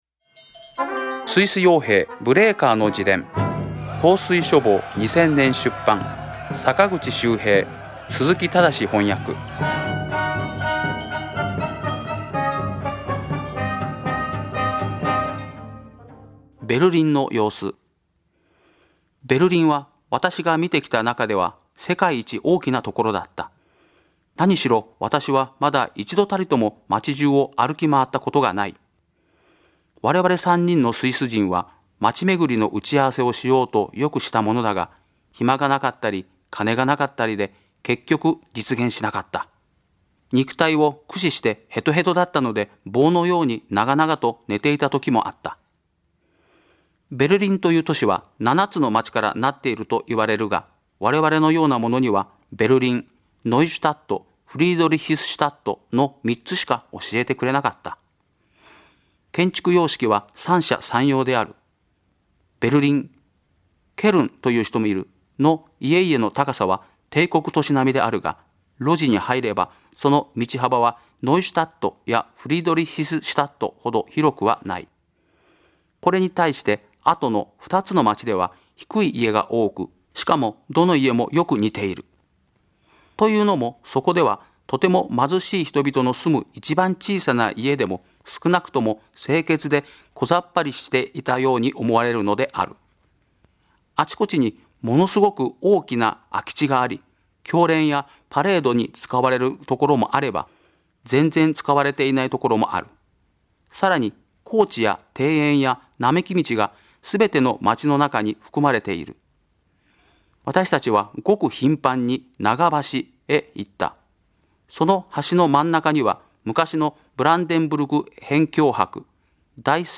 朗読『スイス傭兵ブレーカーの自伝』第51回